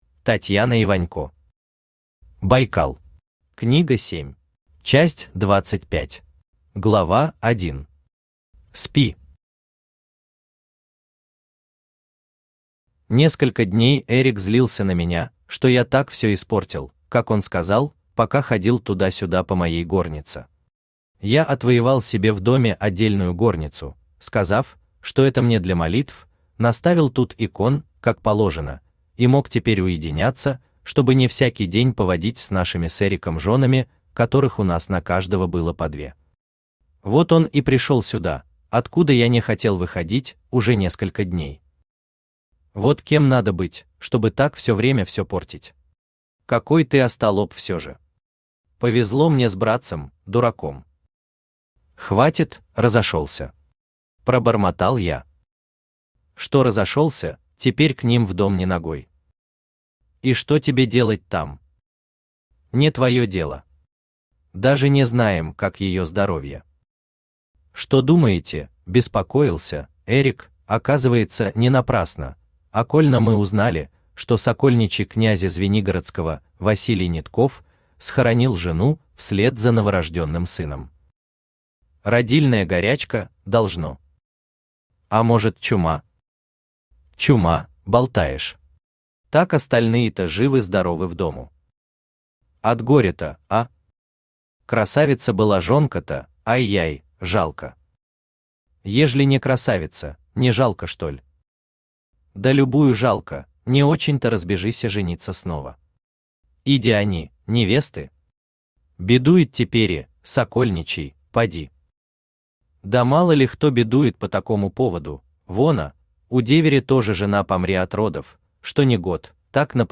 Аудиокнига Байкал. Книга 7 | Библиотека аудиокниг
Книга 7 Автор Татьяна Вячеславовна Иванько Читает аудиокнигу Авточтец ЛитРес.